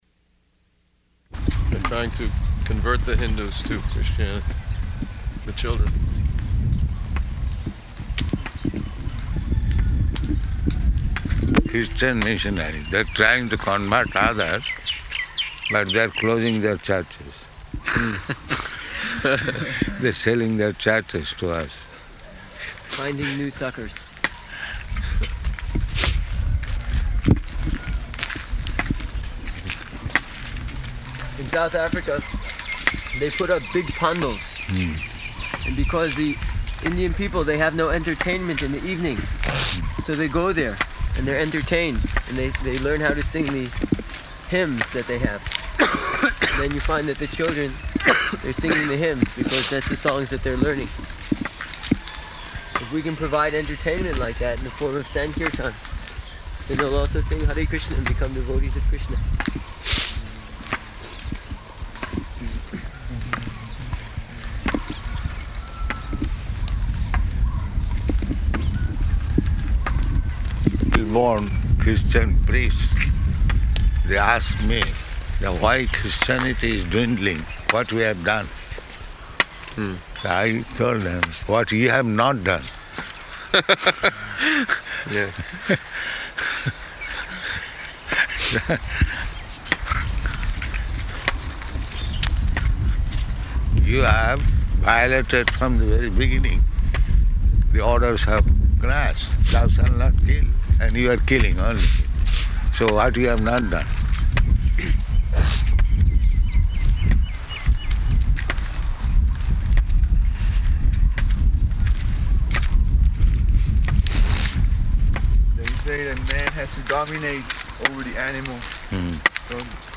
-- Type: Walk Dated: October 4th 1975 Location: Mauritius Audio file